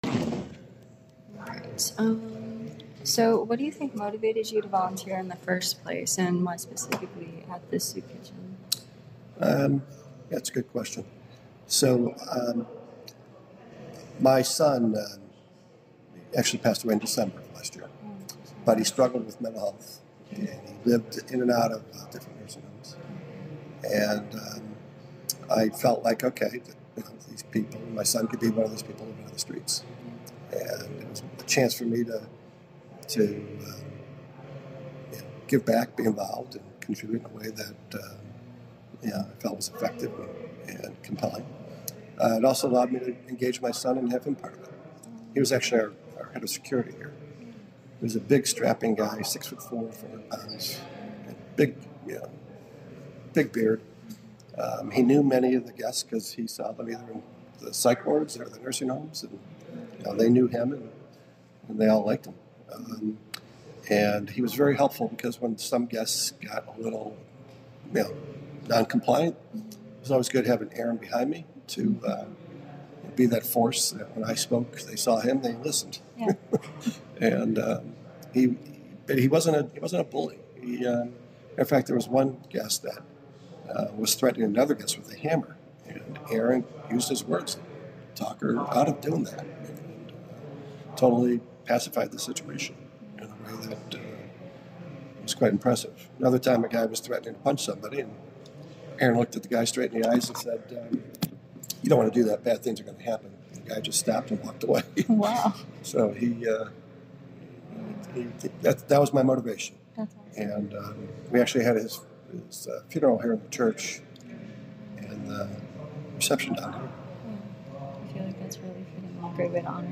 This is an oral history interview